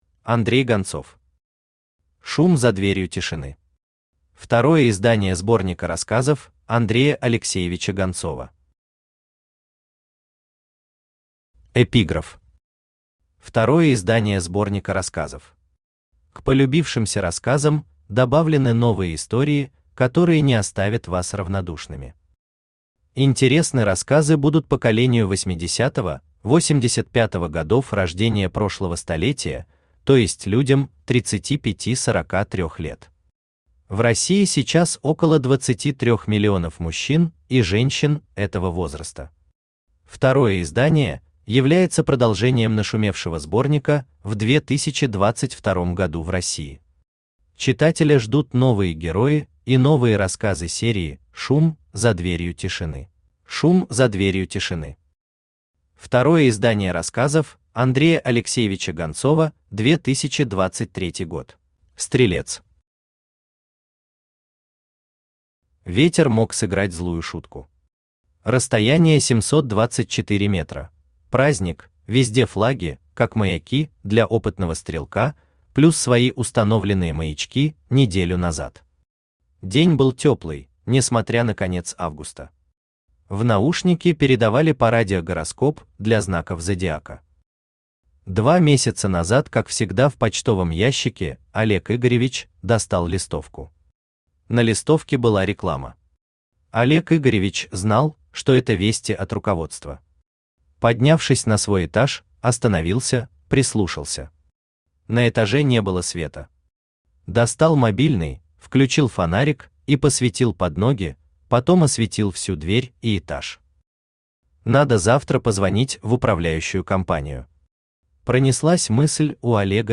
Аудиокнига «Шум за дверью тишины».
Второе издание сборника рассказов Андрея Алексеевича Гонцова Автор Андрей Гонцов Читает аудиокнигу Авточтец ЛитРес.